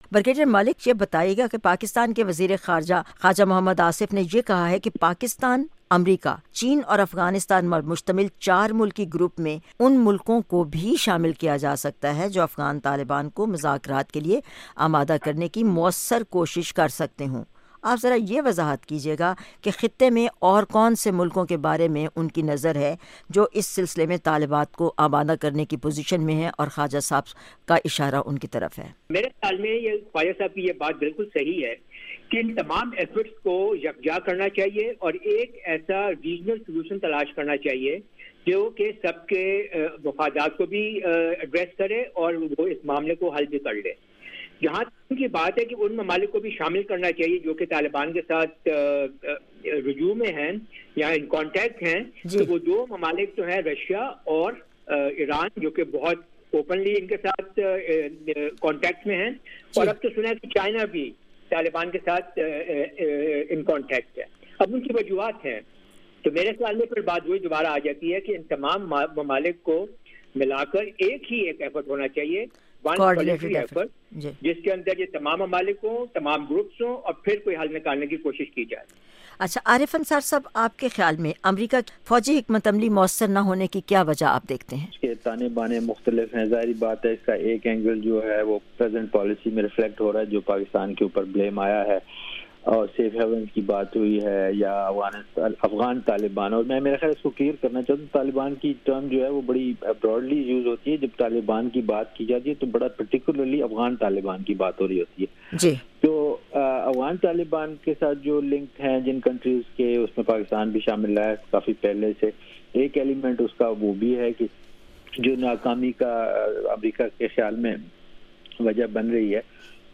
JR discussion: Experts' viewpoint on lasting peace in Afghanistan